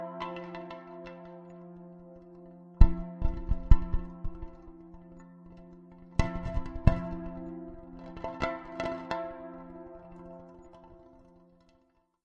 焰火" F S fw.m11.f6a
描述：烟花，使用颗粒合成和复杂的过滤器处理，原始样本： by WIM
标签： 过滤器 焰火 粒状合成 加工
声道立体声